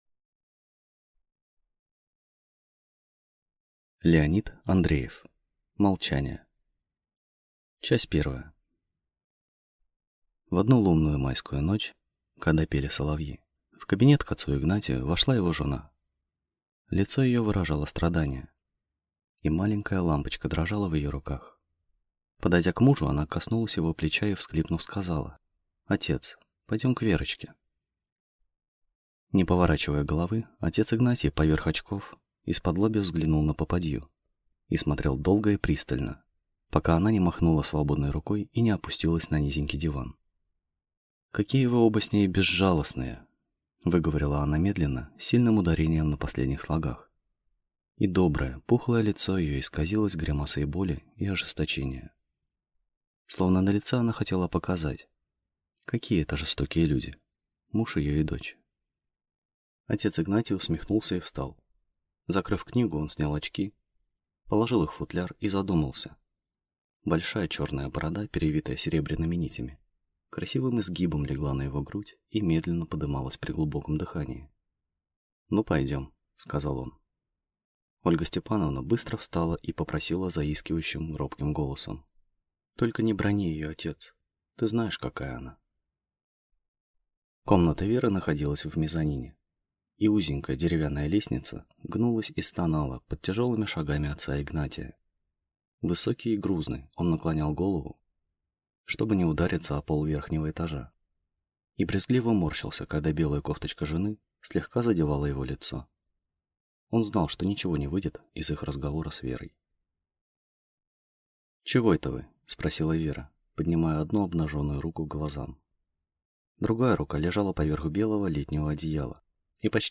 Aудиокнига Молчание
Читает аудиокнигу